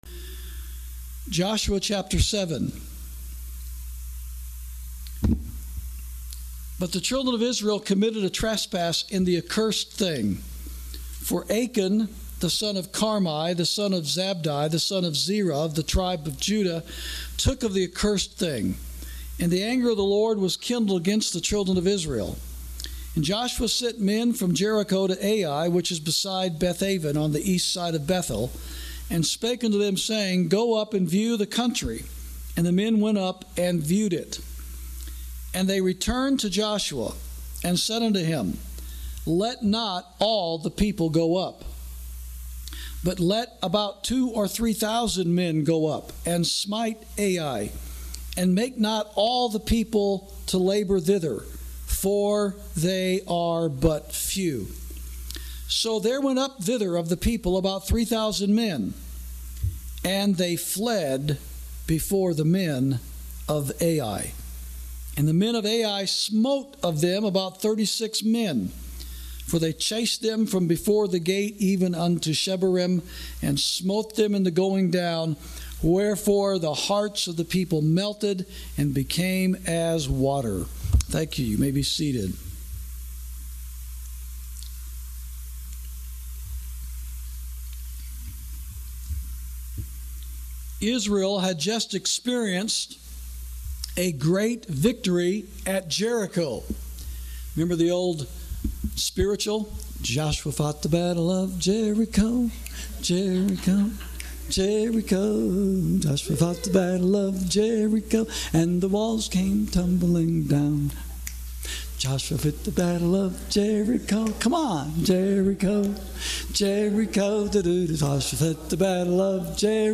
Sermons > Take All The People With Thee